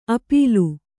♪ apīlu